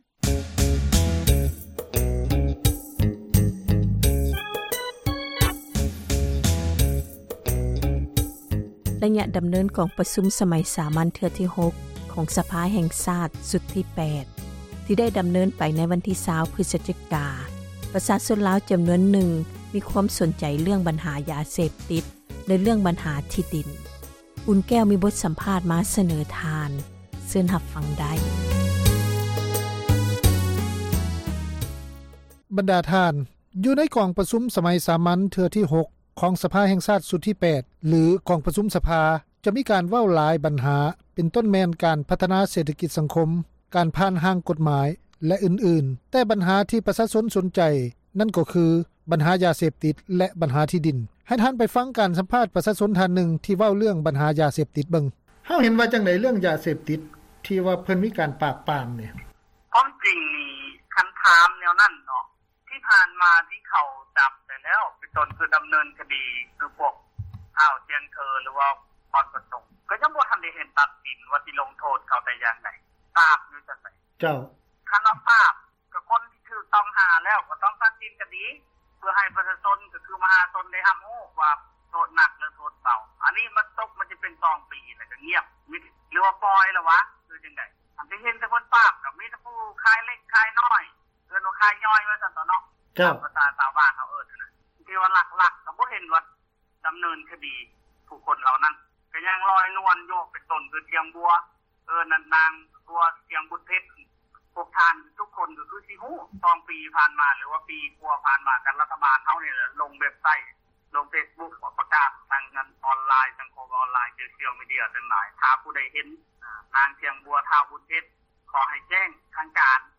ໃນກອງປະຊຸມສໄມສາມັນ ເທື່ອທີ 6 ຂອງ ສະພາແຫ່ງຊາດ ຊຸດທີ 8 ຫລື ກອງປະຊຸມ ສະພາ ຈະມີການເວົ້າຫລາຍບັນຫາ ເປັນຕົ້ນແມ່ນ ການພັທນາເສຖກິດ-ສັງຄົມ, ການຜ່ານຮ່າງກົດໝາຍ ແລະອື່ນໆ, ແຕ່ ບັນຫາທີ່ ປະຊາຊົນສົນໃຈ ນັ້ນກໍຄື ບັນຫາ ຢາເສພຕິດ ແລະ ບັນຫາ ທີ່ດິນ. ເຊີນທ່ານຟັງ ການສຳພາດ ປະຊາຊົນ ທ່ານນຶ່ງ ທີ່ເວົ້າເຖິງບັນຫາ ຢາເສບຕິດ ໃນ ສປປ ລາວ...